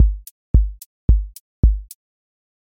QA Test — four on floor
four on floor QA Listening Test house Template: four_on_floor April 17, 2026 ← Back to all listening tests Audio four on floor Open MP3 directly Selected Components macro_house_four_on_floor voice_kick_808 voice_hat_rimshot Test Notes What This Test Is Four on floor Selected Components macro_house_four_on_floor voice_kick_808 voice_hat_rimshot